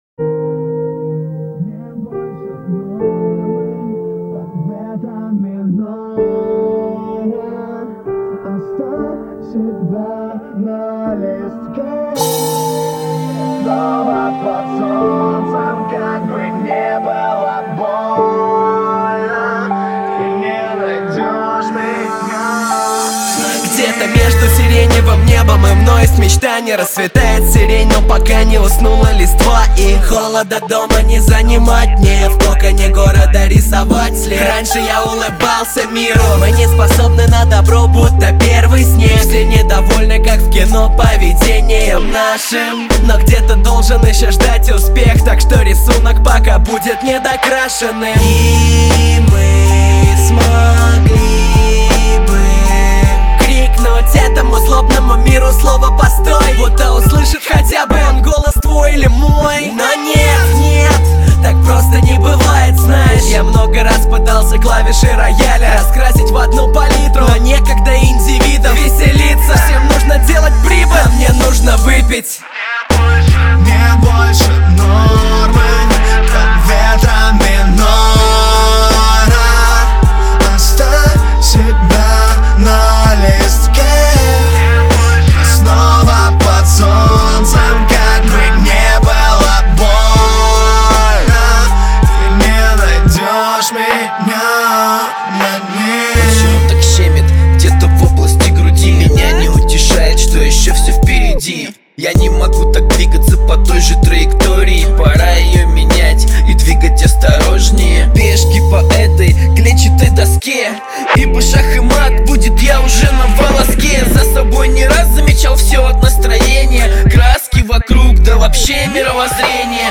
Категория: Русский рэп 2016